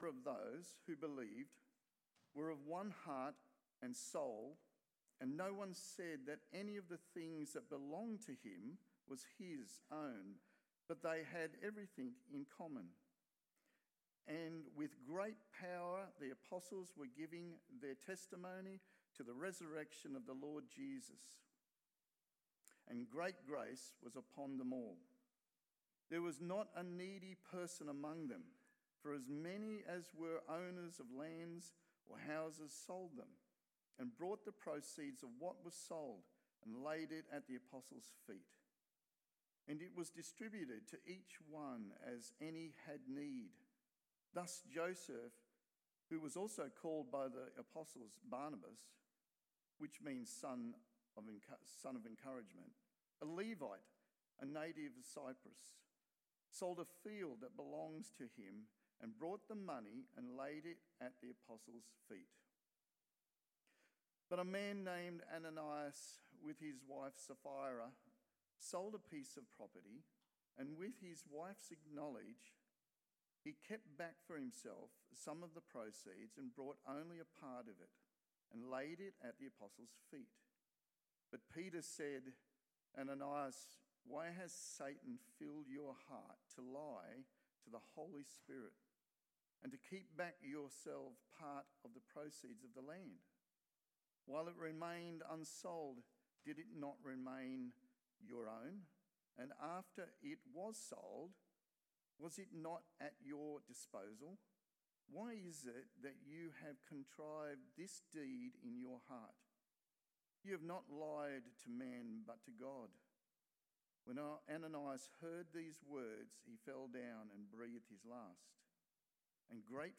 The Radical Love Of The Spirit-Filled Church AM Service